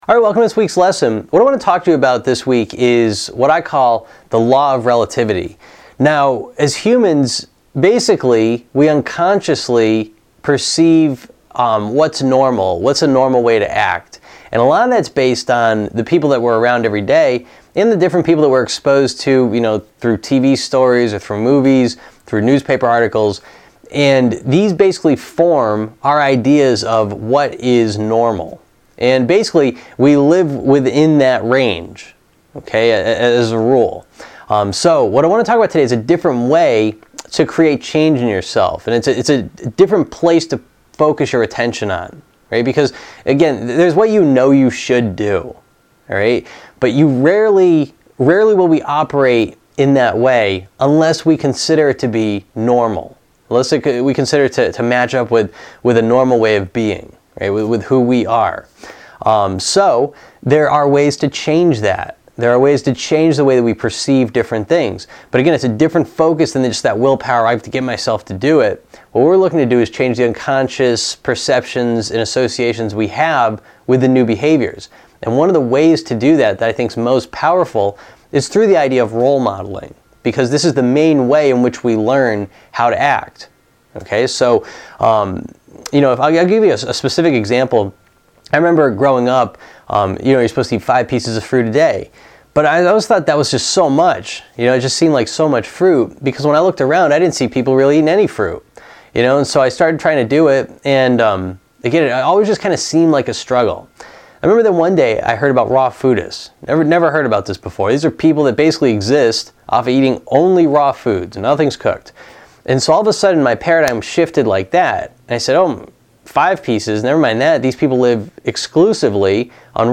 Lesson #46